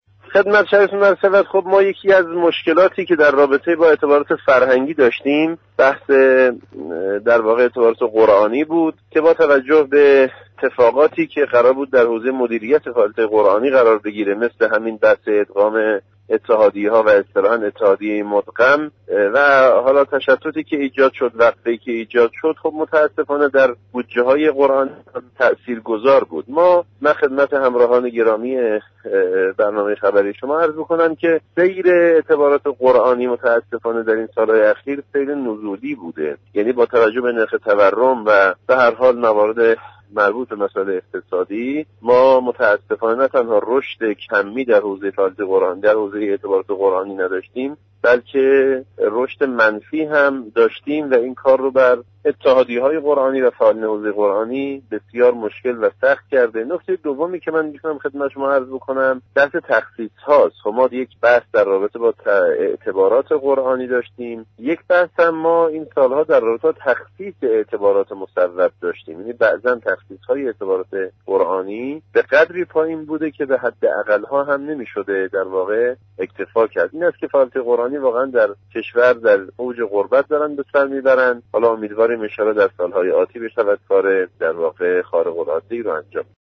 حجت‌الاسلام آزادیخواه در گفتگو با خبر رادیو معارف گفت كاهش بودجه / فعالیت موسسات قرانی را با مشكل مواجه كرده است.